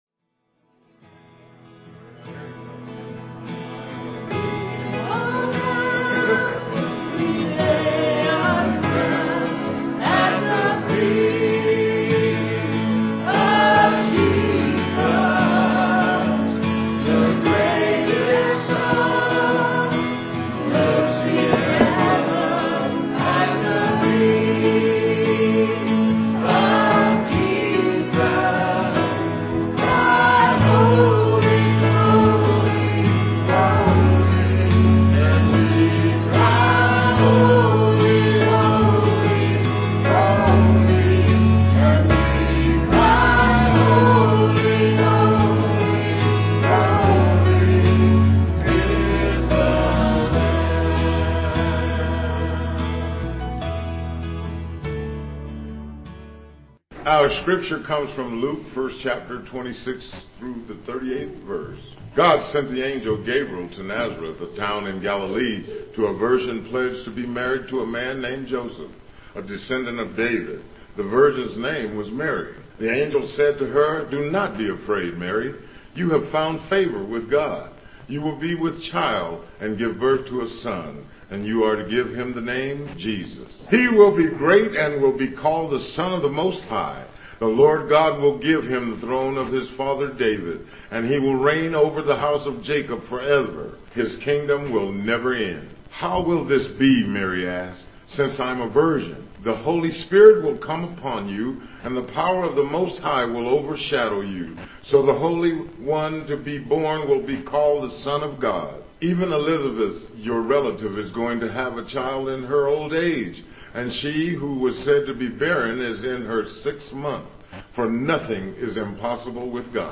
Guitar and vocal solo